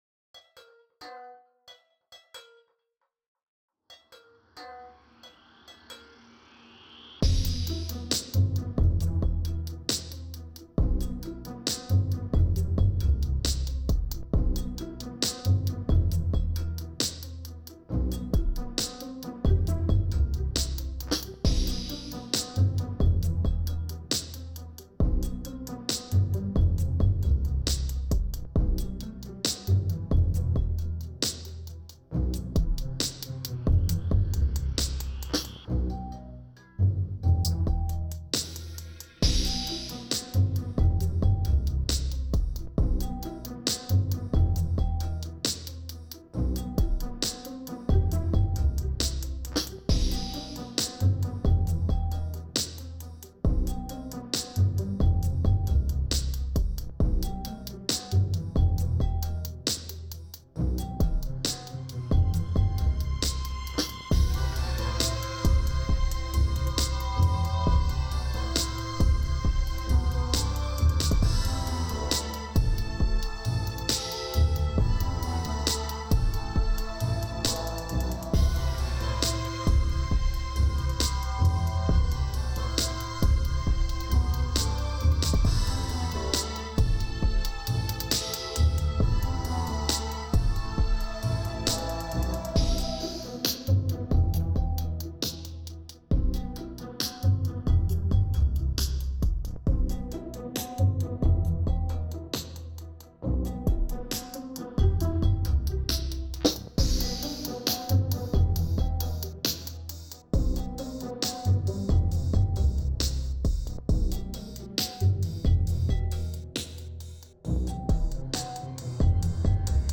Alle Spuren sind für Ambisonics fünfter Ordnung ausgelegt, das heißt jede Spur hat 36 Input-Kanäle.
So konnte ich heute meinen Beat im CUBE in einem spektakulären 3-dimensionalen Umfeld mischen und jeder Komponente möglichst einen passenden Platz im Raumspektrum zuordnen.
Zum Hörbeispiel: Hierbei handelt es sich um ein konvertiertes binaurales Format, das heißt nur mit Kopfhörer kann der gewünschte Effekt erzielt werden. Die erste Hälfte des Beispiels zeigt die Platzierung der Instrumente im Klangspektrum, ab 1:32min habe ich das gesamte Klangspektrum rotieren lassen, was ebenfalls einen interessanten und deutlichen räumlichen Effekt zur Folge hat, dafür fällt hier die räumliche Anordnung der einzelnen Instrumente weg. Generell ist der Mix relativ leise, also keine Hemmungen beim Aufdrehen! beat8 3D binaural
beat8-3D-binaural.wav